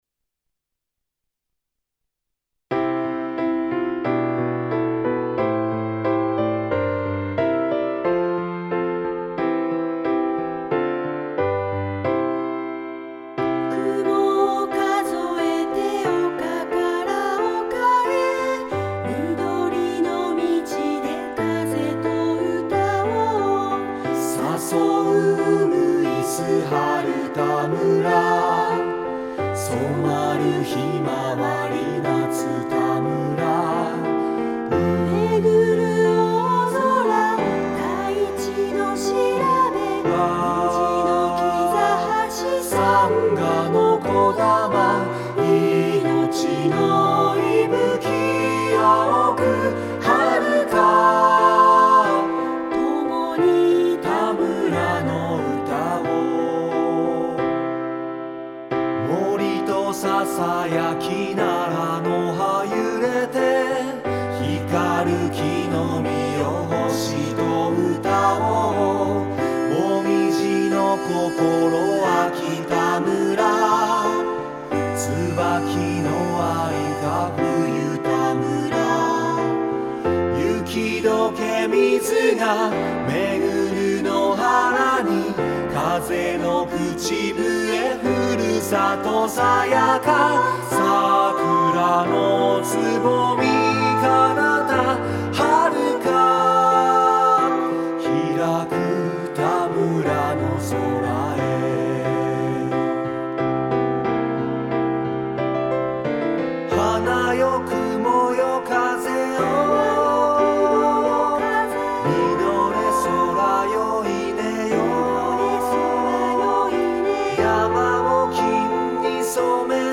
混声二部合唱